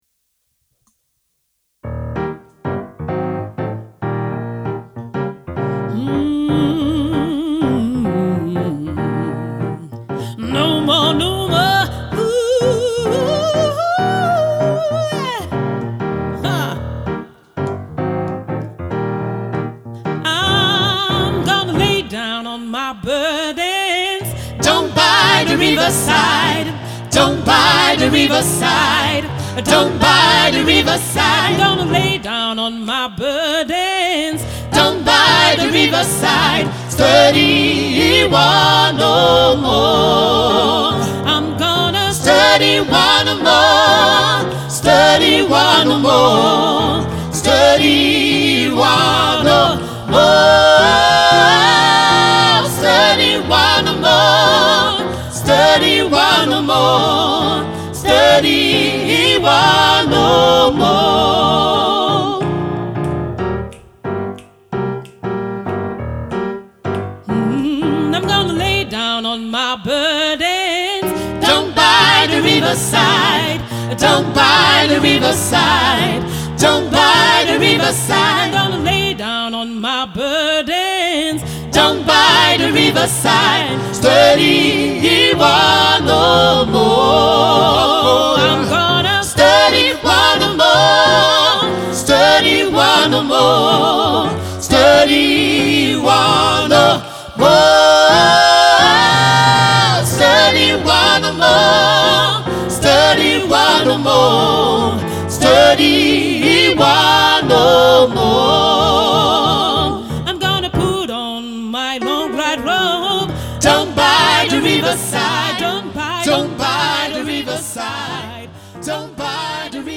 Le trio Gospel DIPJOY
DipJoy est un groupe de gospel qui réunit trois chanteurs et un pianiste , amoureux de la musique et passionnés depuis toujours.